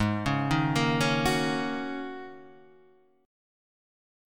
G#9sus4 chord